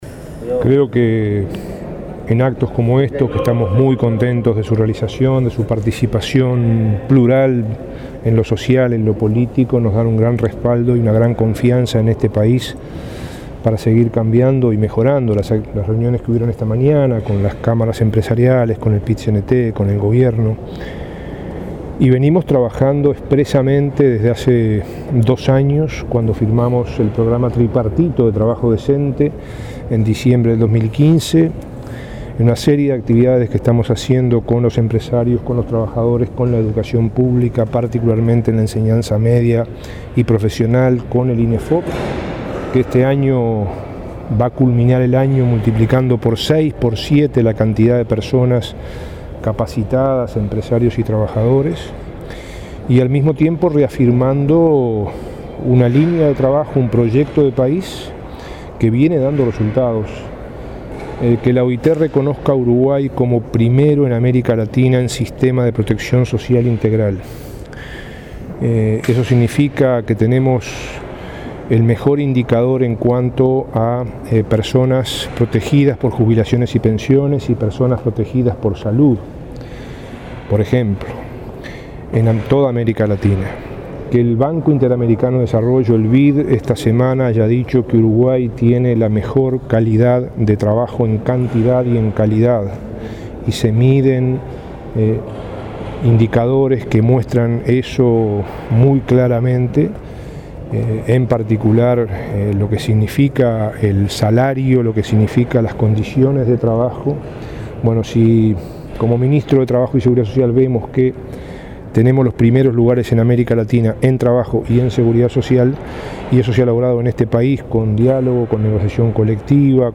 El ministro de Trabajo, Ernesto Murro, en la conferencia del director general de la OIT, Guy Ryder, destacó que la OIT y el BID han ubicado a Uruguay en los primeros lugares de América Latina en trabajo y seguridad social. Argumentó que se logró en base a diálogo y negociación colectiva.